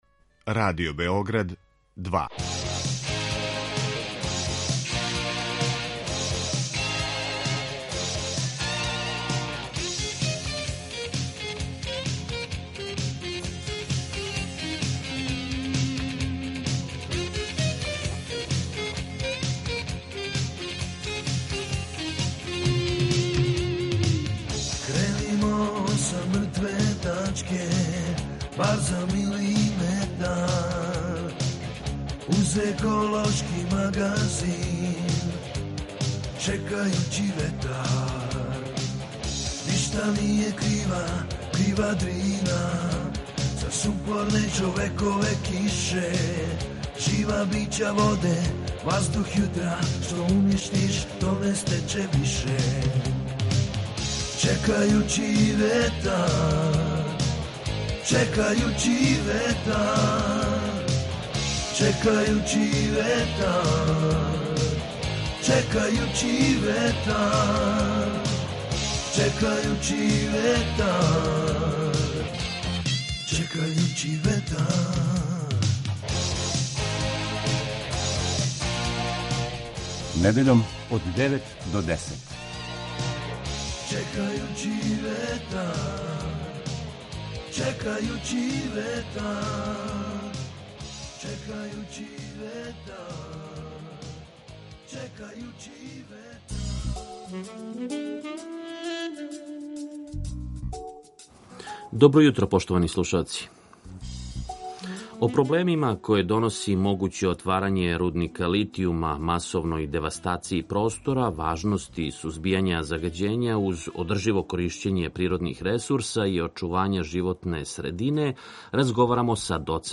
ekološki magazin Radio Beograda 2 koji se bavi odnosom čoveka i životne sredine, čoveka i prirode.